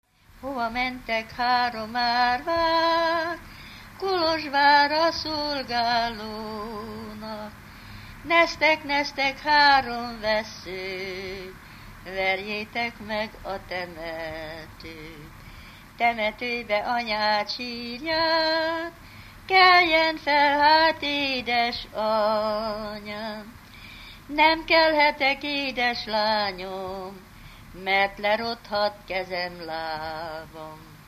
Alföld - Békés vm. - Békésszentandrás
ének
Műfaj: Ballada
Stílus: 1.2. Ereszkedő pásztordalok